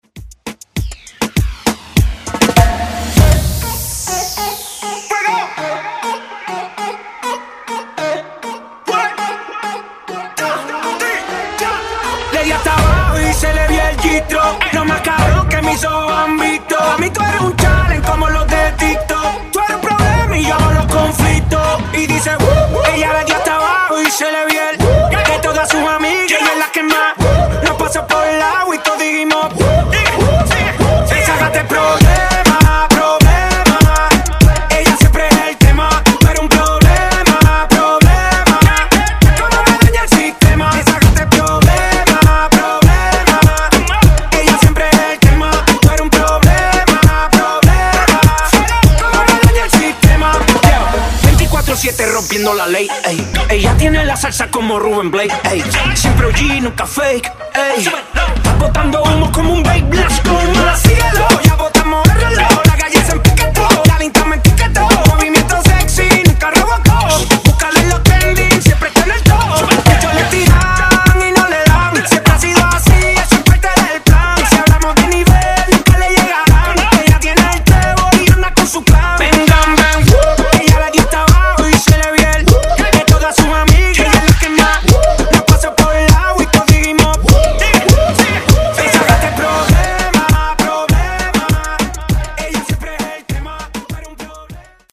Genres: LATIN , RE-DRUM , REGGAETON